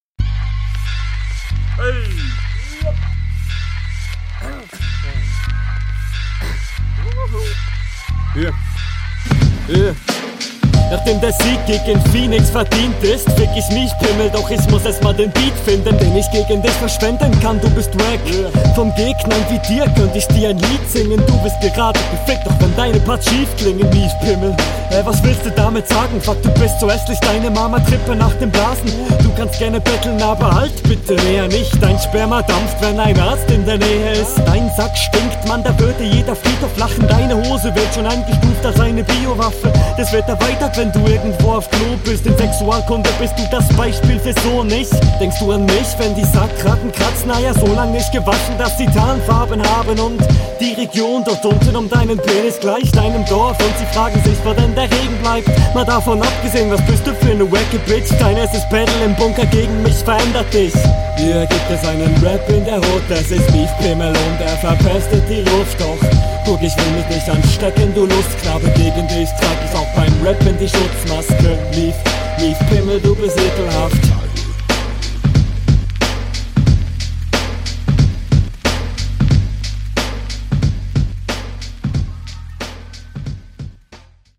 Uuh, schöner Beat!
deine stimme passt ganz gut zum beat und du bist im takt, flowed also alles …